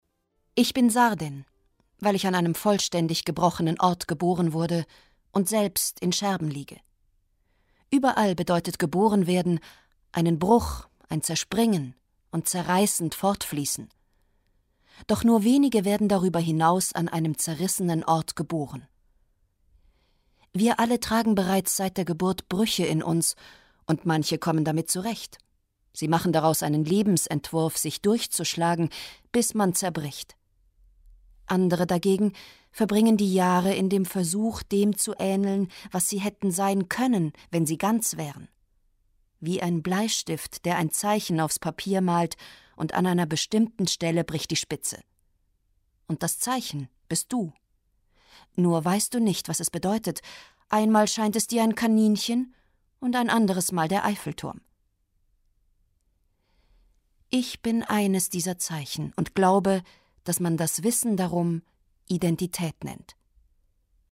Literatur